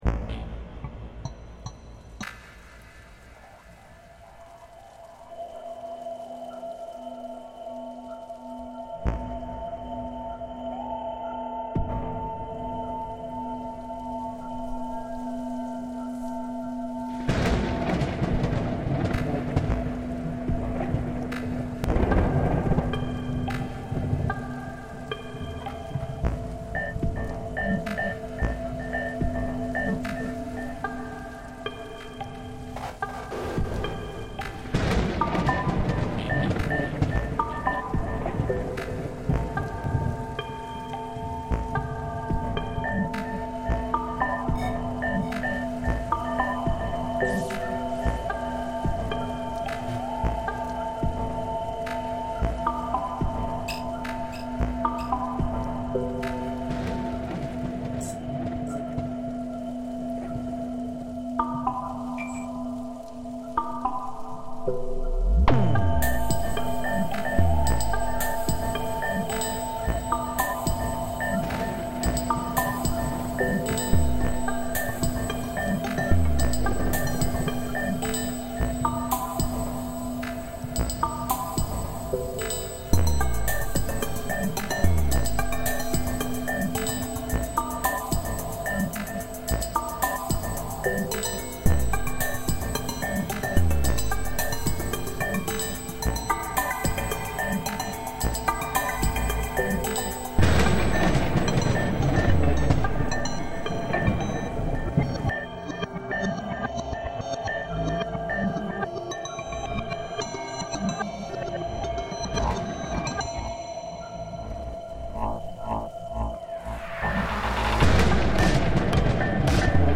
Prescott Valley thumderstorm reimagined